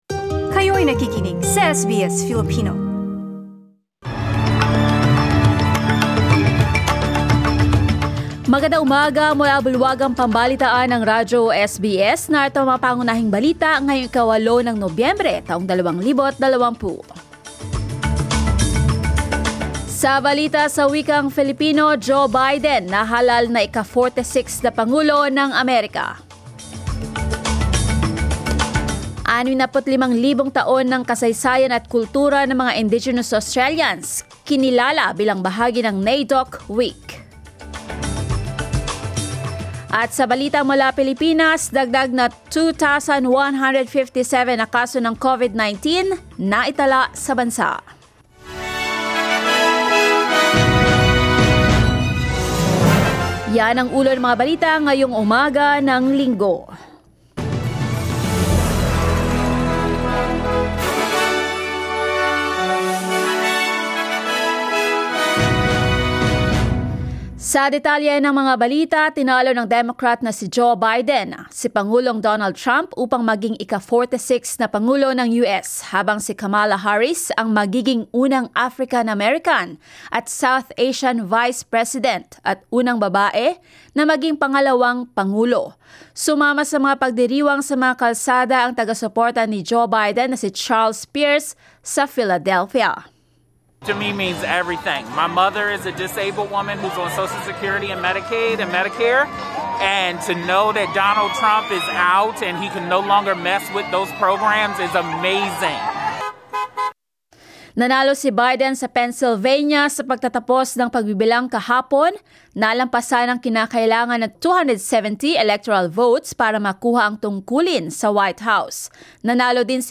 SBS News in Filipino, Sunday 08 November